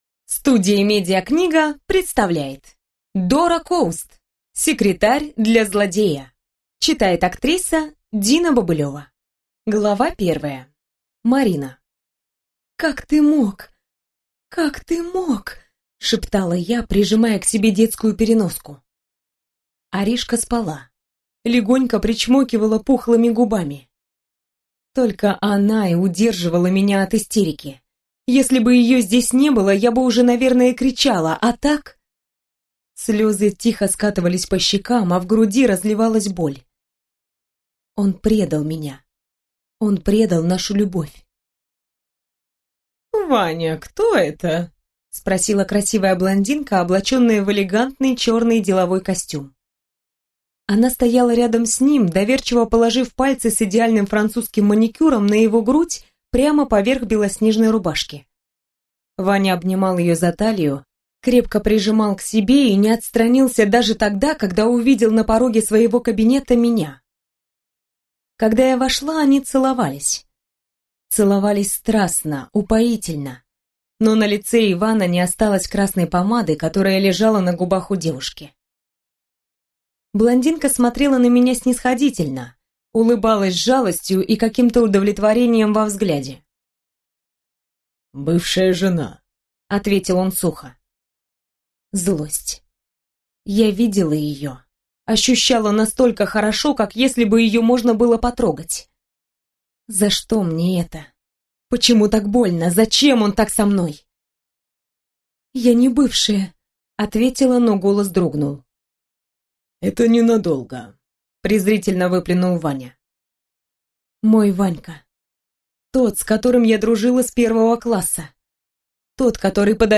Аудиокнига Секретарь для злодея | Библиотека аудиокниг